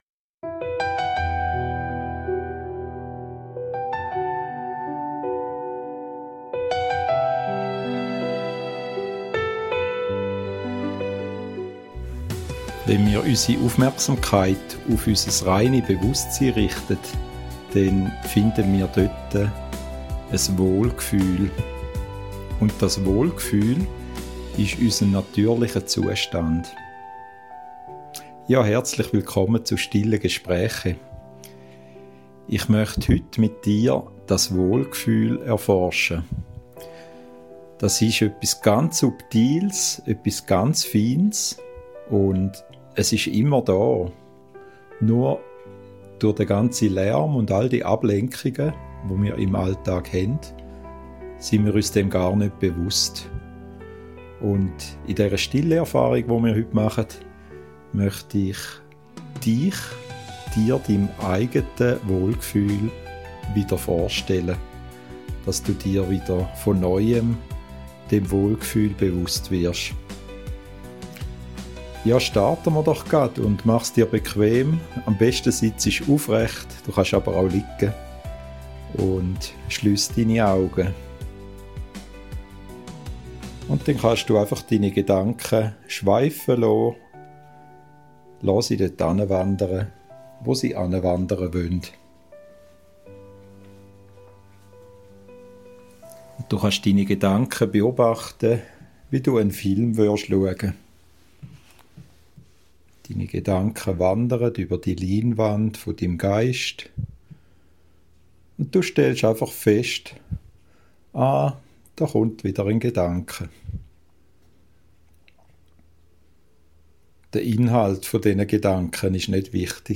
Geführte Stille-Übung – Die Wohlgefühl-Technik für inneren Frieden ~ STILLE GESPRÄCHE | Bewusstsein • Wahrheit • Freiheit Podcast